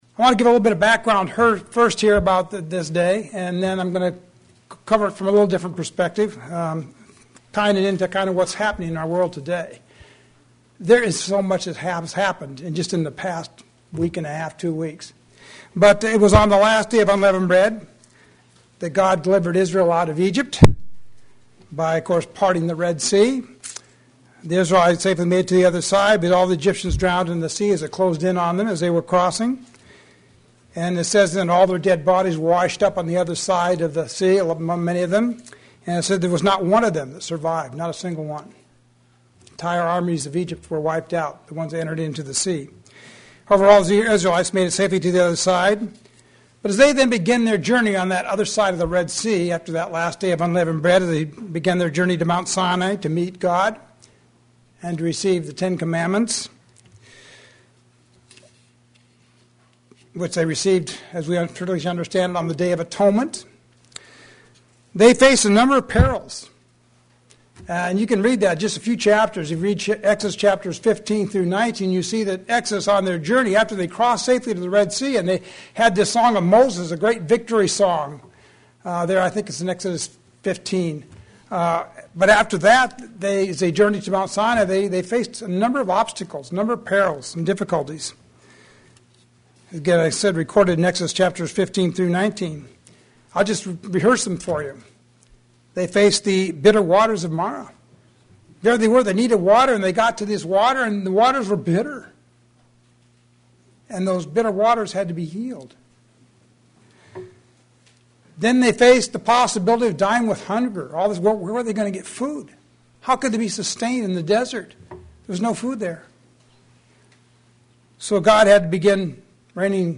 Where must we abide? sermon Transcript This transcript was generated by AI and may contain errors.